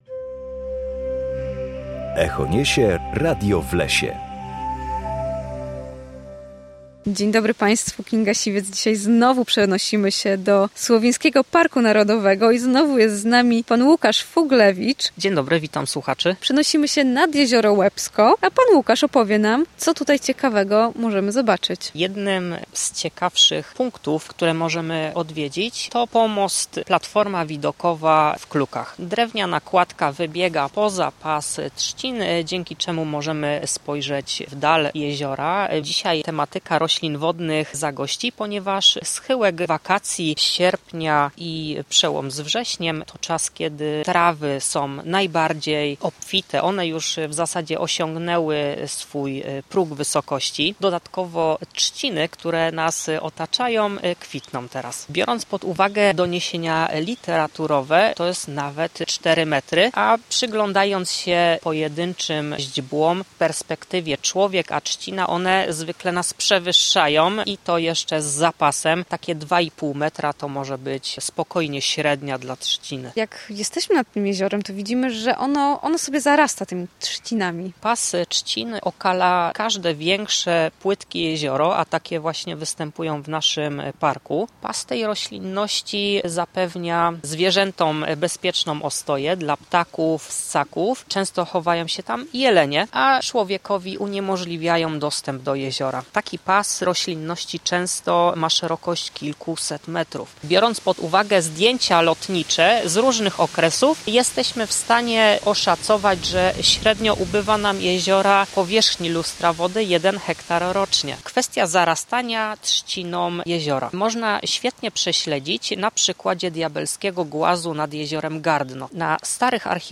W każdą środę o godzinie 7:20 na antenie Studia Słupsk rozmawiamy o naturze i sprawach z nią związanych.